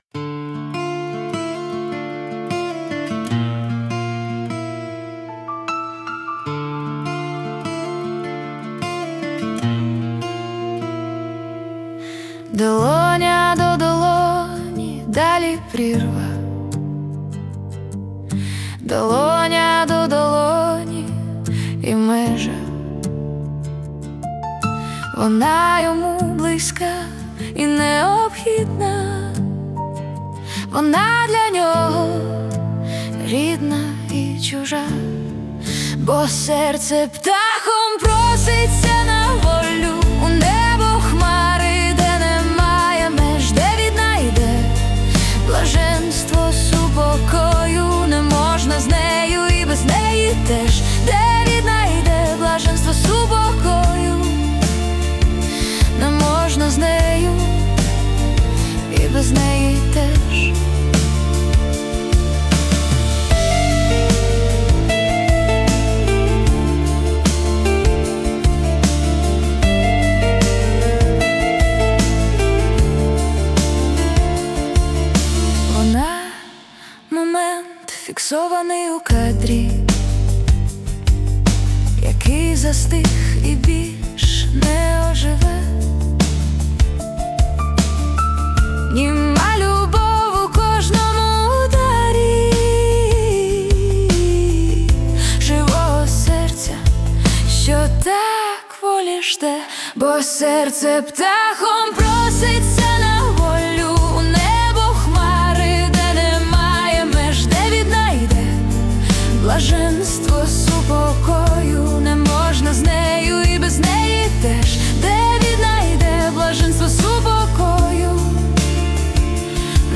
(Музика і виконання ШІ)
СТИЛЬОВІ ЖАНРИ: Ліричний